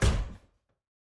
Footstep Robot Large 2_09.wav